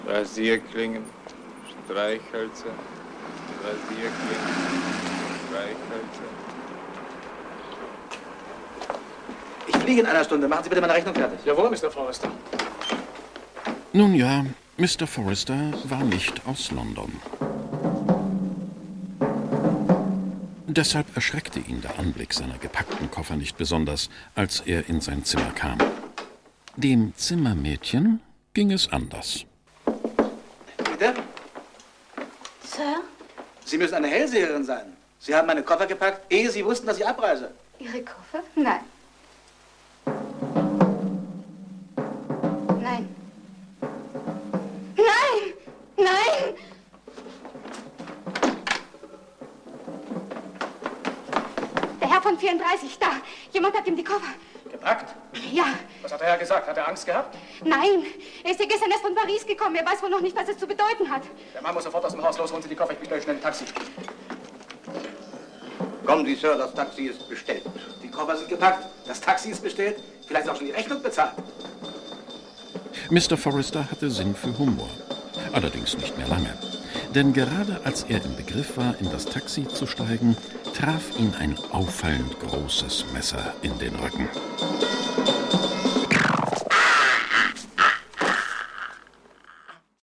Hörbuch Bryan Edgar Wallace - Krimibox, Bryan Edgar Wallace.